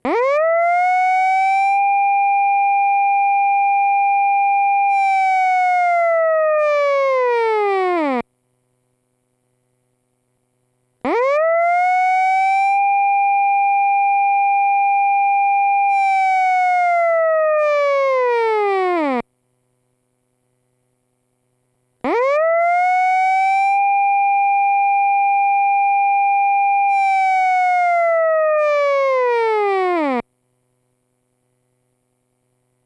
サイレン（5秒吹鳴6秒休止×3回）
(注意)試聴の際は、音量に御注意ください